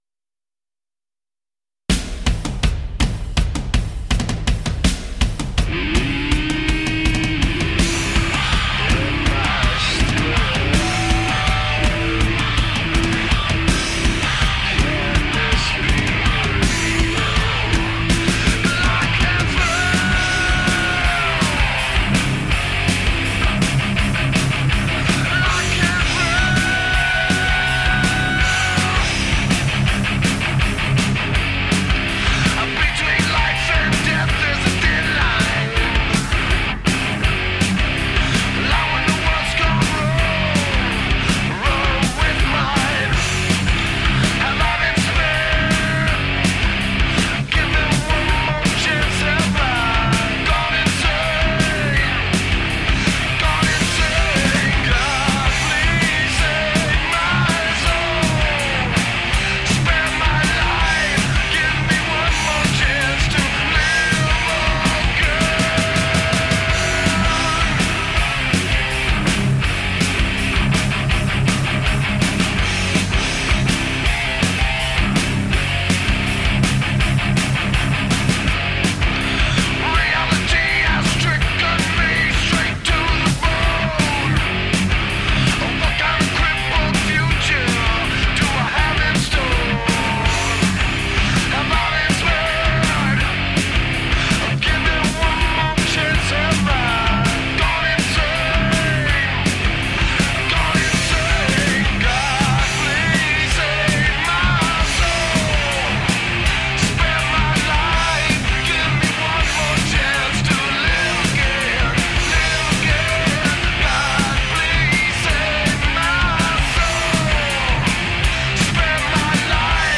Heavy metal with strong melodies and big riffs.
Tagged as: Hard Rock, Other, Intense Metal